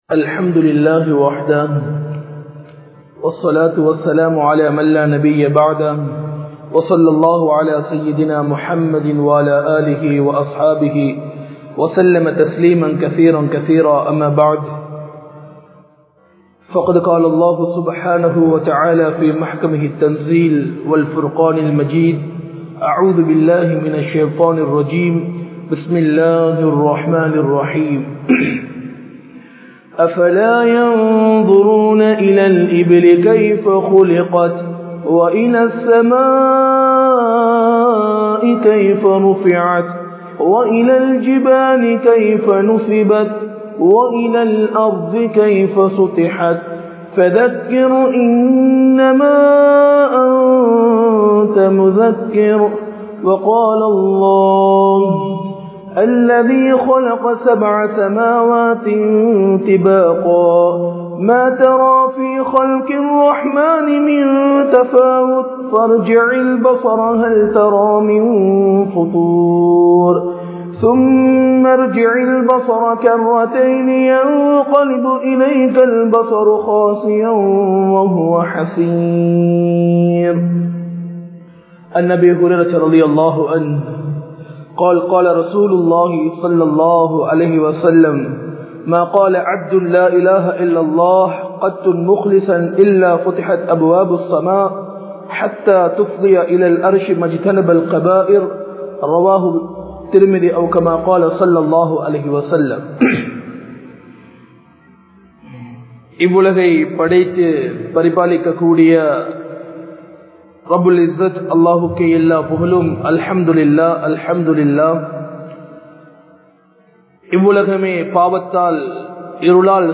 Vaanaththin Vaasalhal Eppoathu Thirakka Padum? (வானத்தின் வாசல்கள் எப்போது திறக்கப்படும்?) | Audio Bayans | All Ceylon Muslim Youth Community | Addalaichenai
Majma Ul Khairah Jumua Masjith (Nimal Road)